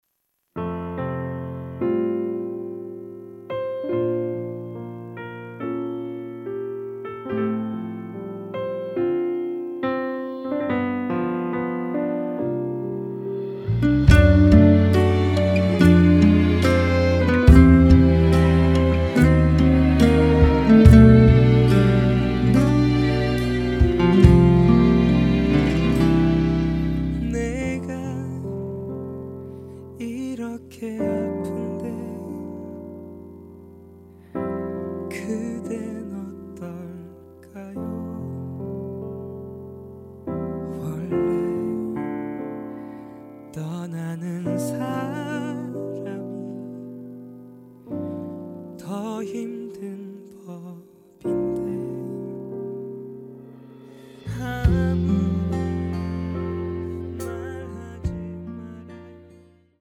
음정 원키 4:12
장르 가요 구분 Voice Cut